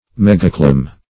Megacoulomb \Meg`a*cou`lomb"\